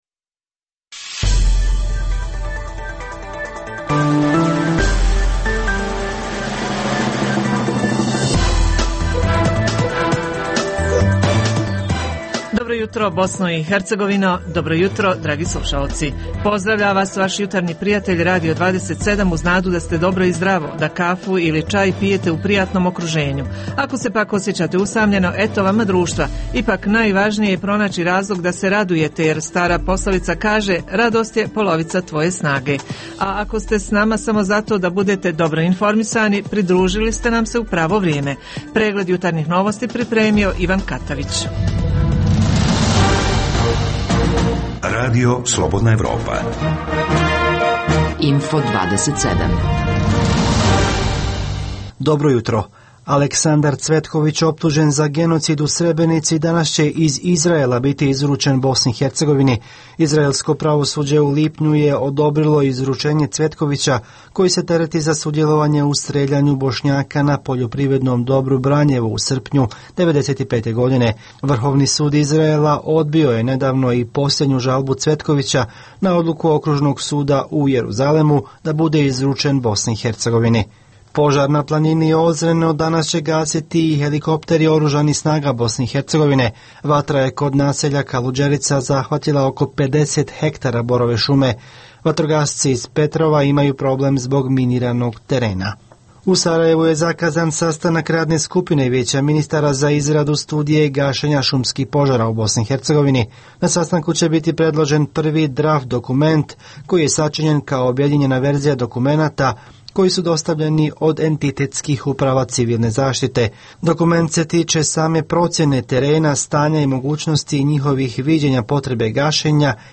U jutarnjem programu objavljujemo: - Uz javljanja reportera o aktuelnim događajima u njihovim sredinama, obrađujemo temu o turizmu. - Dopisnici iz Prijedora, Doboja, Jablanice i Brčko Distrikta javljaju ko su turisti, odakle najčešće dolaze, šta ih posebno zanima.
- Redovna rubrika je „Filmoskop“ - Uz tri emisije vijesti, možete uživati i u ugodnoj muzici.